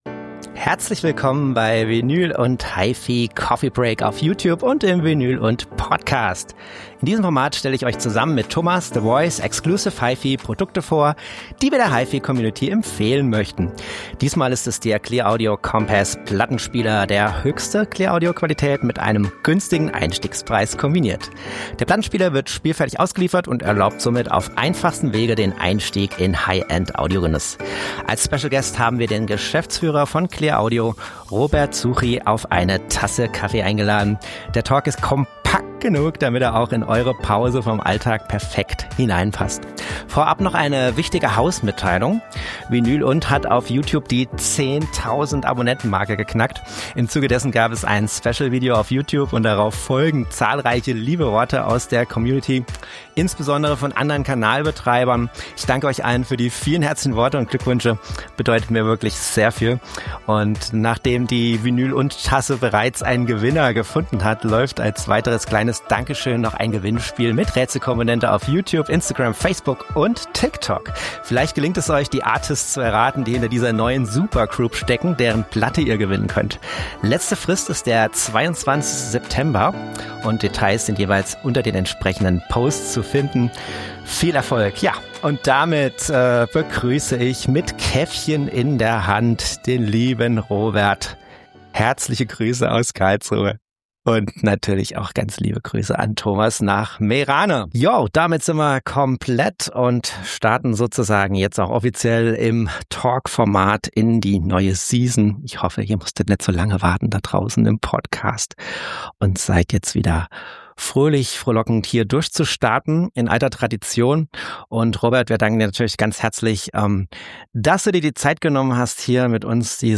Der Talk ist kompakt genug, damit er auch in eure Pause vom Alltag perfekt hinein passt. Als kleine Überraschung sprechen wir außerdem noch über die brandneue clearaudio smart double matrix Plattenwaschmaschine...